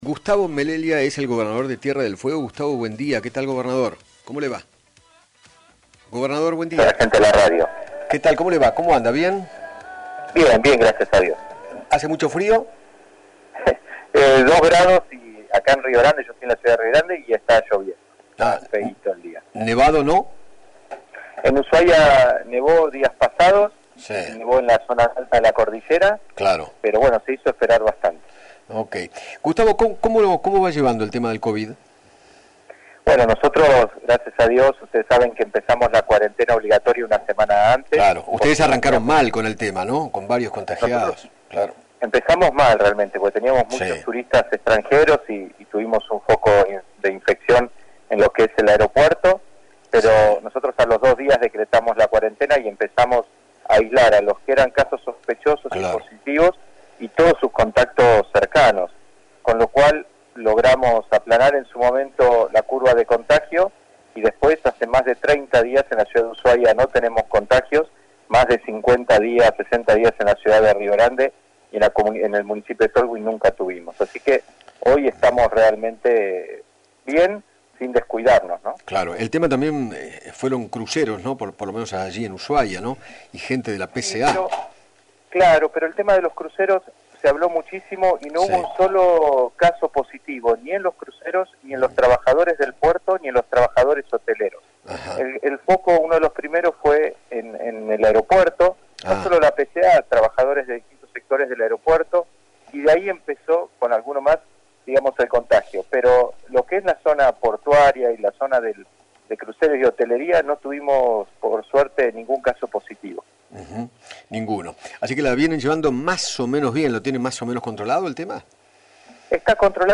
Gustavo Melella, gobernador de Tierra del Fuego, dialogó con Eduardo Feinmann sobre la vuelta a la normalidad en la provincia y aseguró que “hace más de 30 días no tenemos nuevos casos de Coronavirus”.